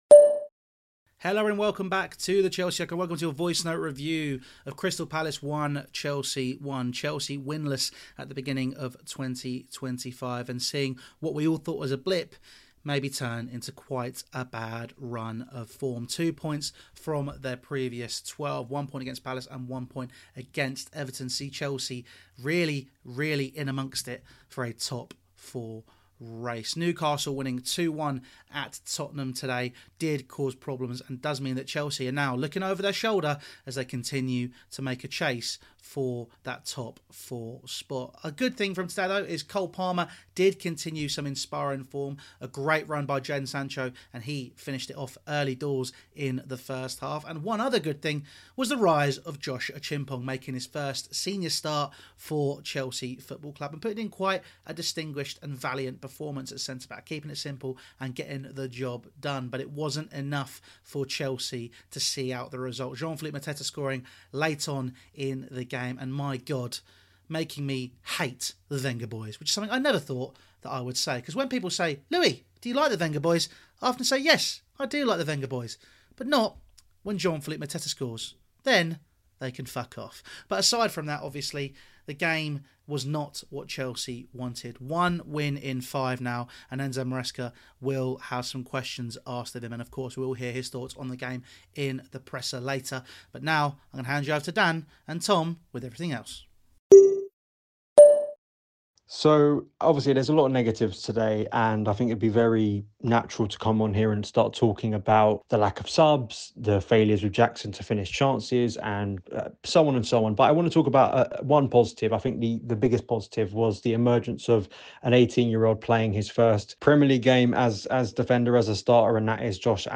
| Crystal Palace 1-1 Chelsea | Voicenote Review